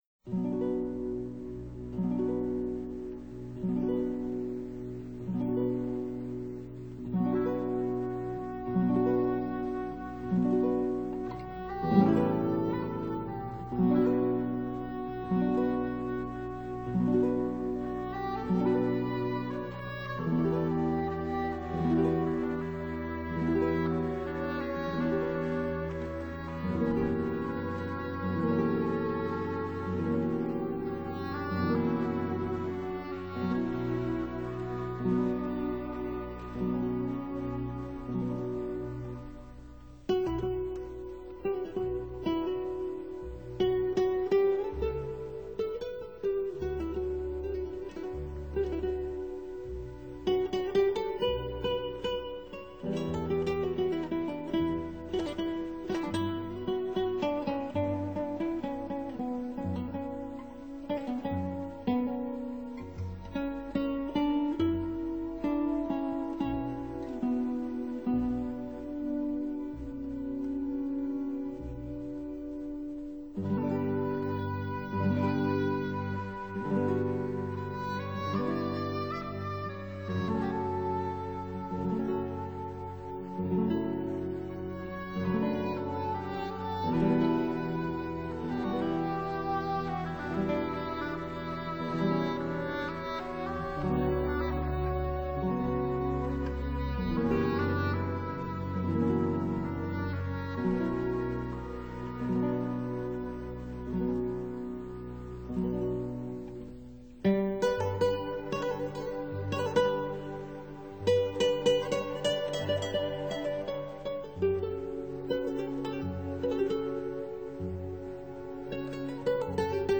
版本：吉他
系为吉他和管弦乐而作，其余的则是吉他独奏作品。
专辑中的作品录于20世纪60年代末和70年代初， 但音乐品质一以贯之的优良.